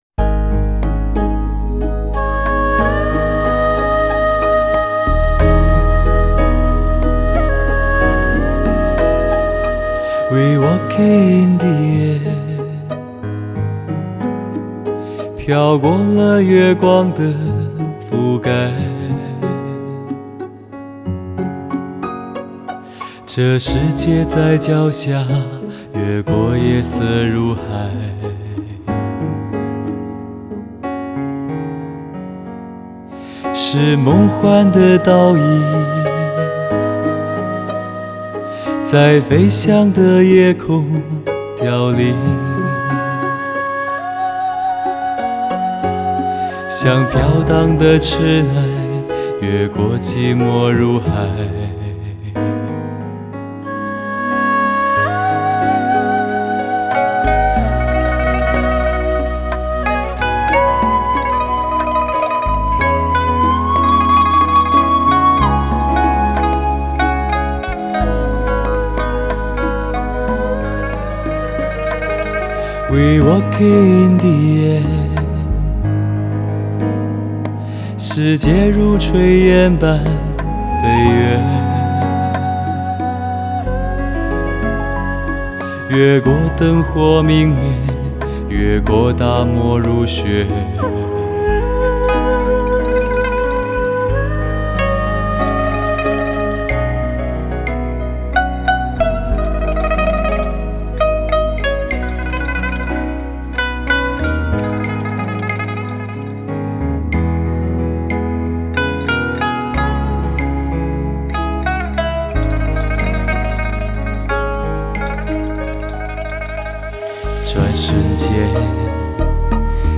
淳厚磁性、带金色光泽的声音再次感动您我